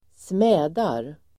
Uttal: [²sm'ä:dar]